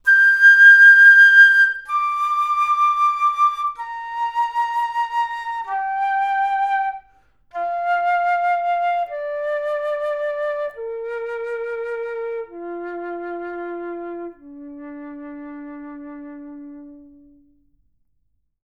Play Flute